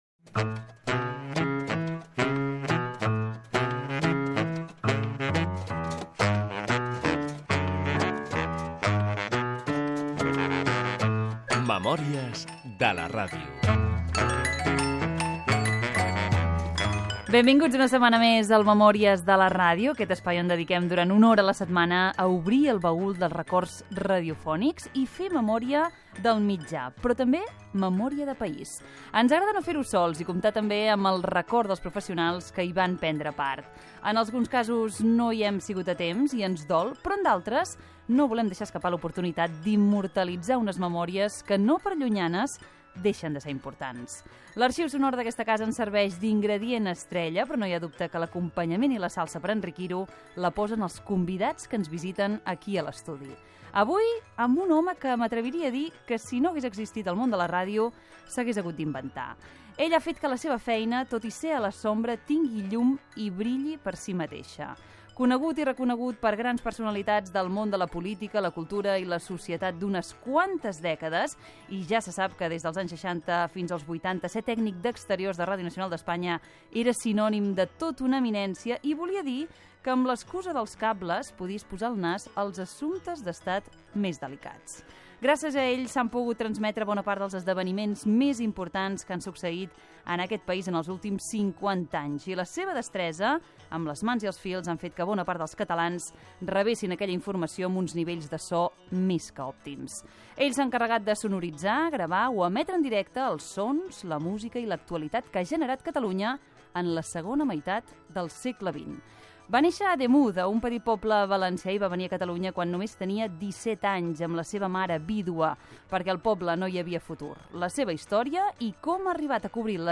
Divulgació
FM